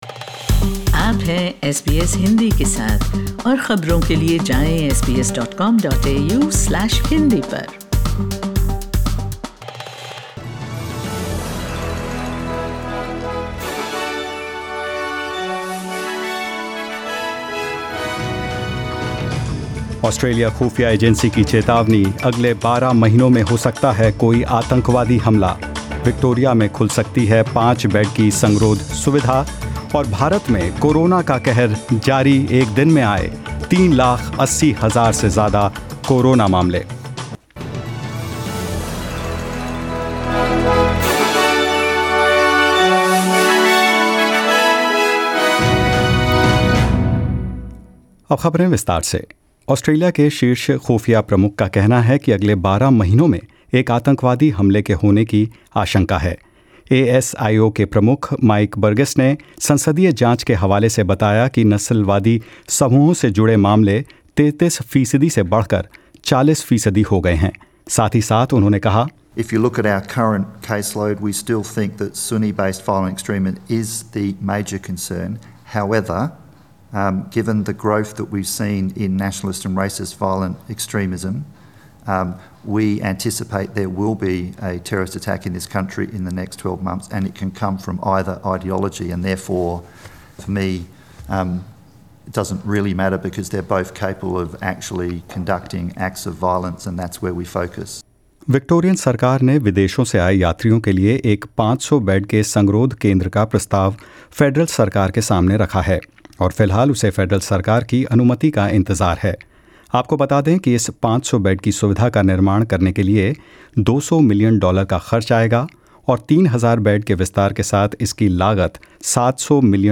In this bulletin: Victoria calls on the federal government to fund a new purpose-built quarantine facility. India records more than 380,000 new Covid-19 cases in last 24 hours ending Thursday.